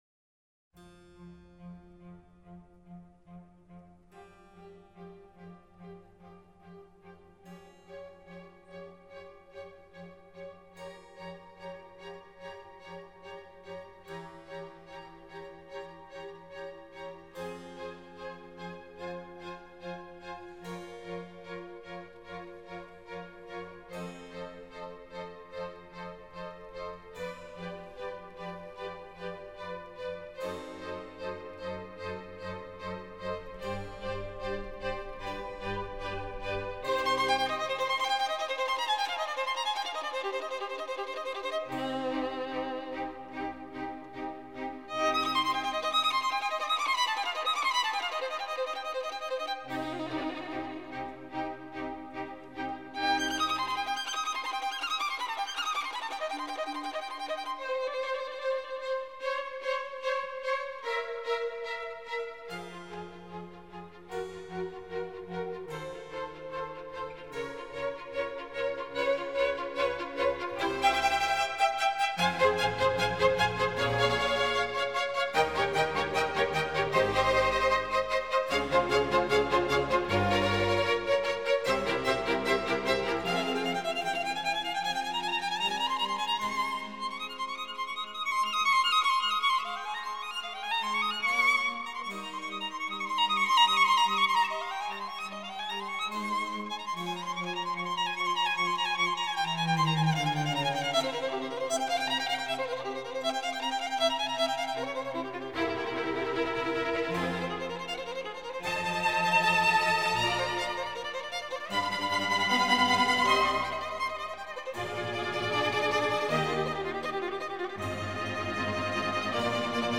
موسیقی بی‌کلام "چهرفصل" بخش "زمستان" موومان اول، آهنگساز: آنتونیو ویوالدی